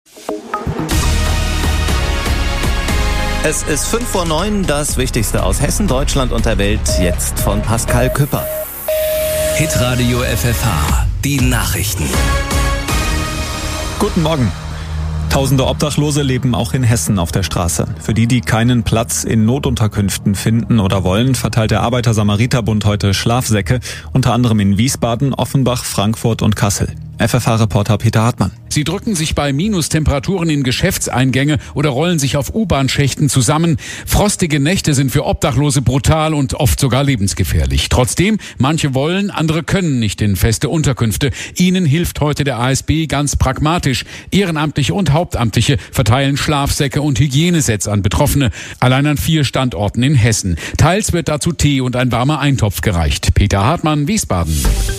FFH_Nachrichten_0855_-_ASB_Schlafsack-Aktion.mp3